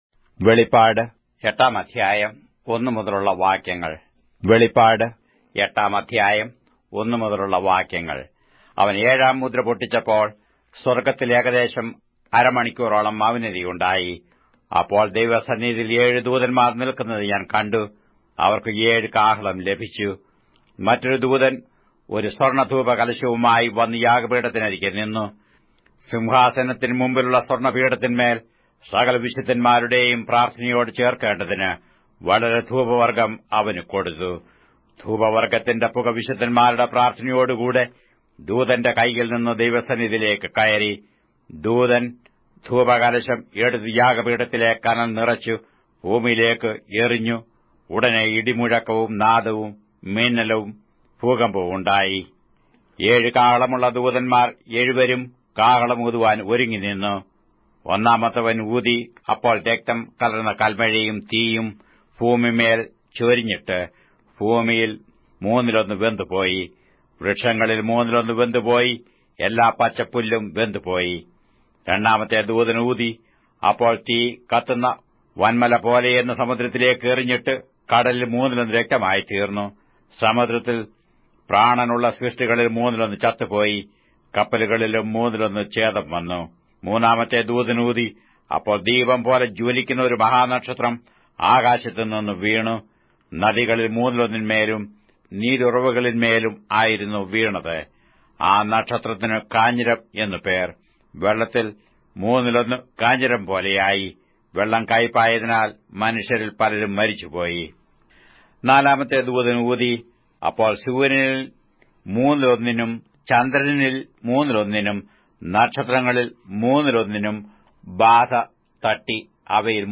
Malayalam Audio Bible - Revelation 16 in Rcta bible version